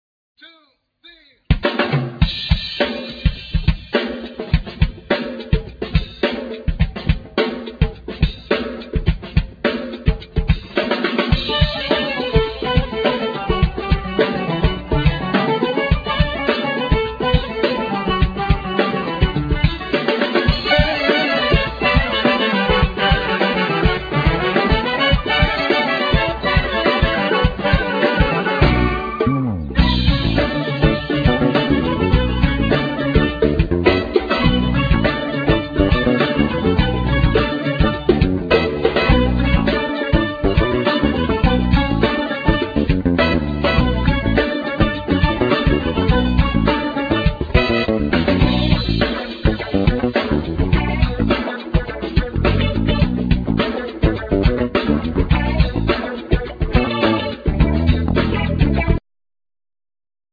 Bass,Guitar
Percussion
Trumpet
Oud